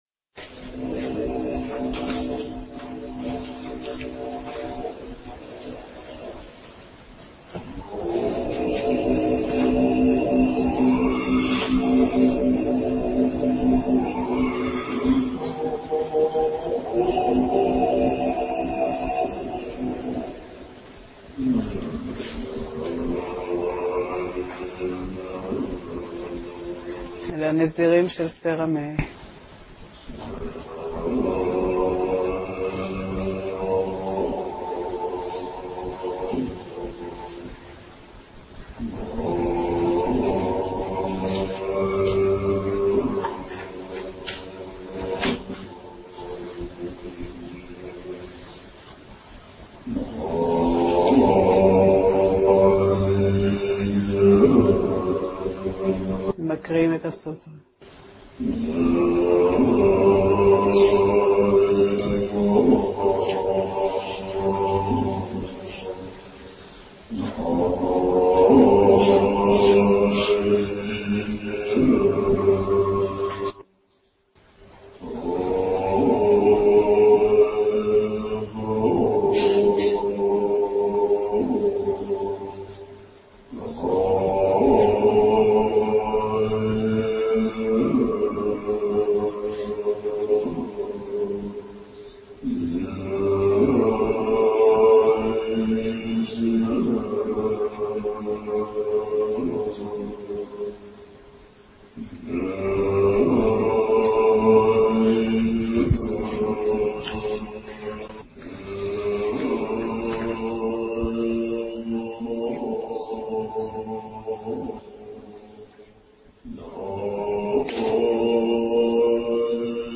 סדנת מדיטציה: סוטרת הלב - שיטה חכמה